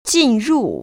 [jìnrù] 진루  ▶